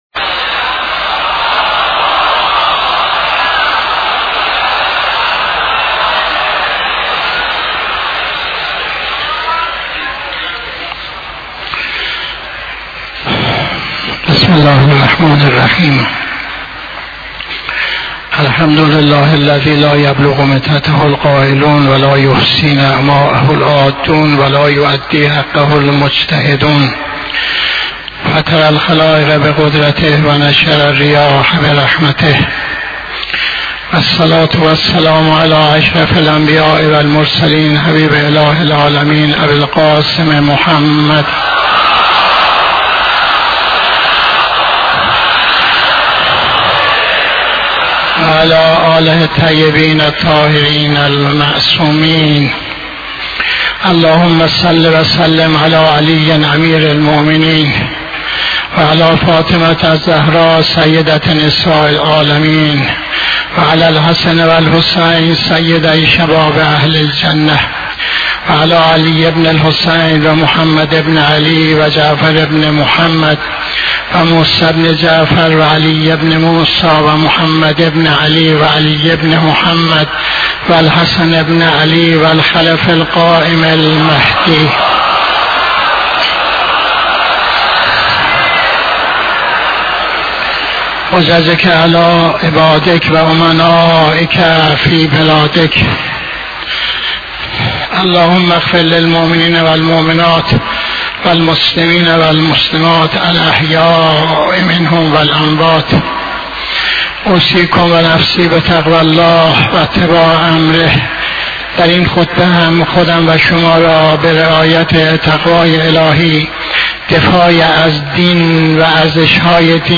خطبه دوم نماز جمعه 19-01-84